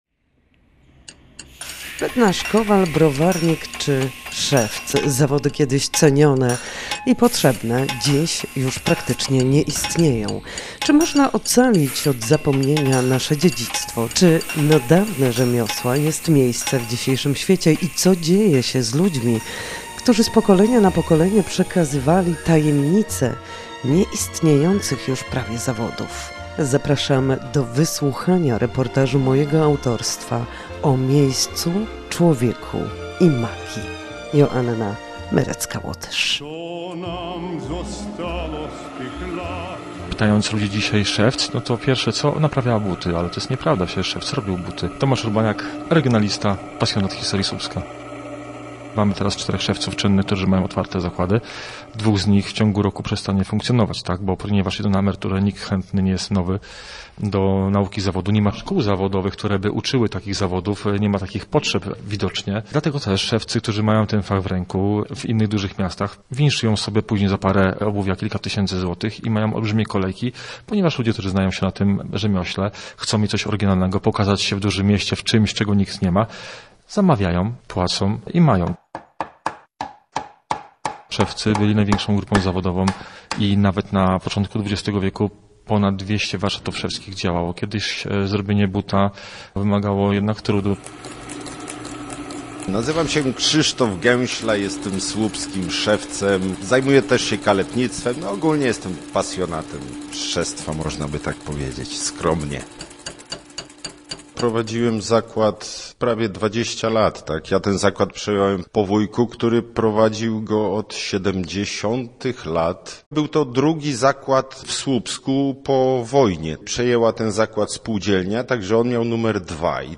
Szewc, który znalazł przestrzeń na warsztat. Posłuchaj reportażu o miejscu, człowieku i magii